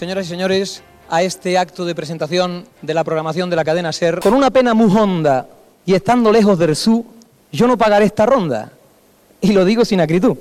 Transmissió, des de l'Hipódromo de la Zarzuela de Madrid, de la Fiesta de la Cadena SER amb motiu de l'estrena de la nova programació.